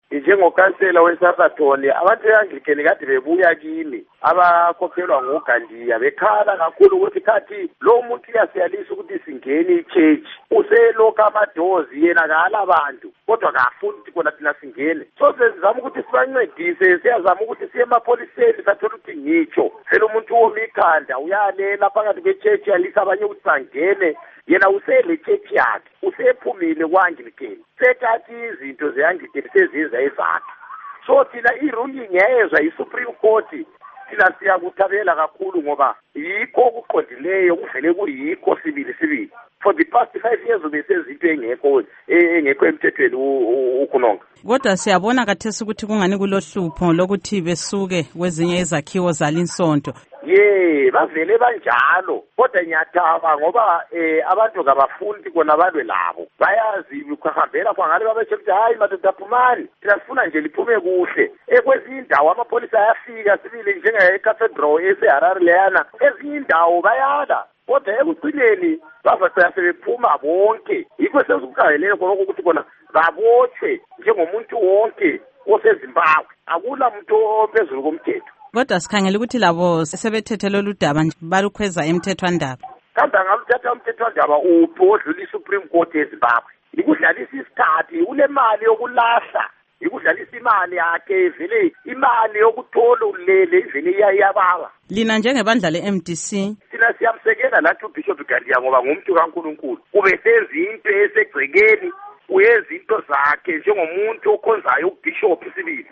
Ingxoxo LoKhansila Peter Moyo